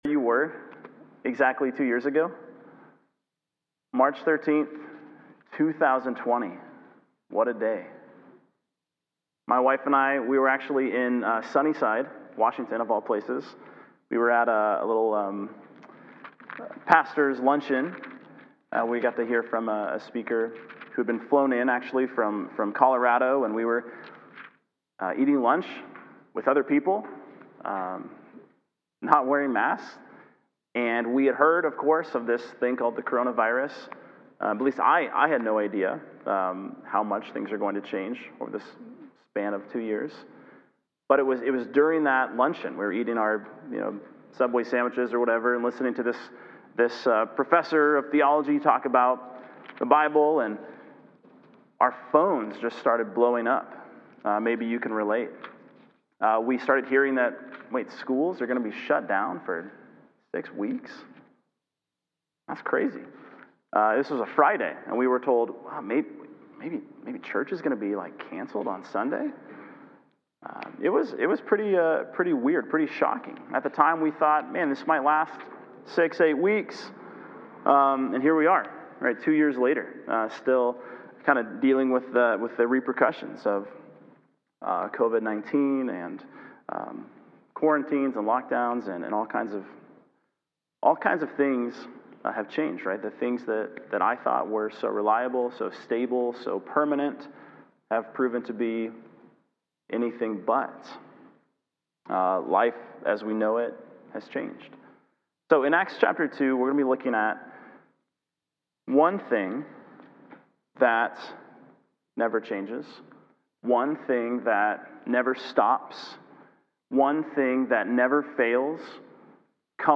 Sermons | Quinault Baptist Church